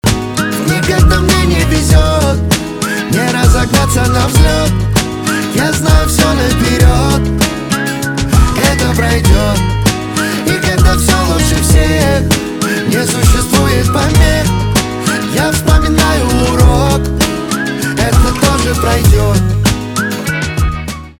поп
гитара , барабаны , позитивные
свист